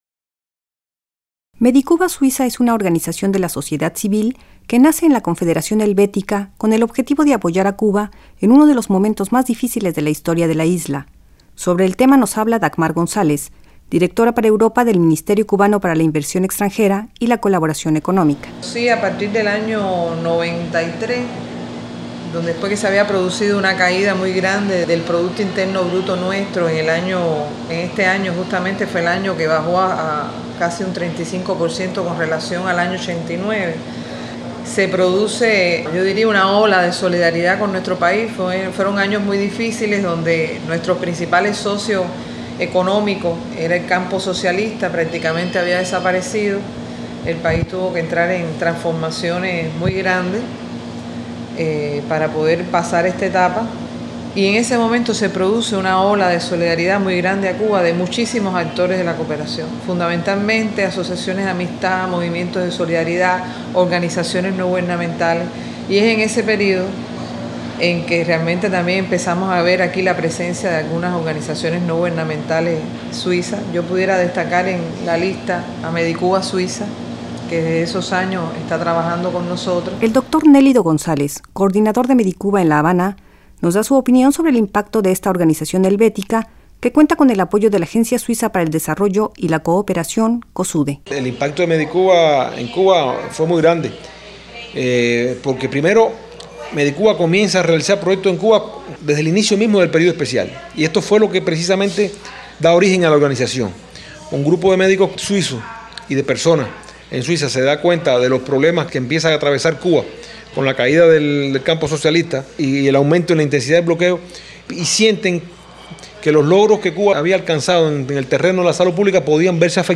La presencia de la cooperación helvética en Cuba adquirió un mayor énfasis a inicios de los años 90 cuando al embargo impuesto por Estados Unidos se sumó la caída del bloque socialista. Entre las acciones de solidaridad con la isla, una de las que ha tenido mayor impacto es la de mediCuba Suiza. Un reportaje